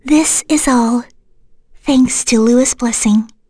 Frey-Vox_Victory.wav